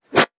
weapon_bullet_flyby_14.wav